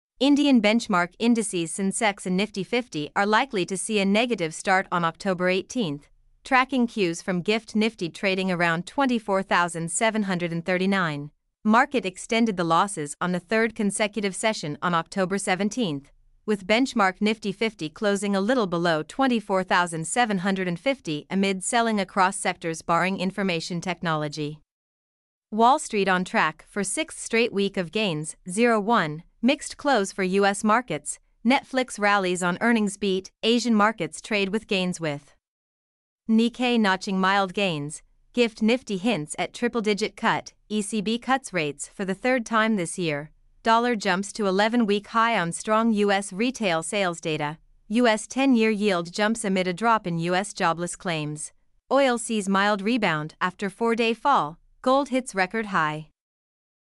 mp3-output-ttsfreedotcom-11.mp3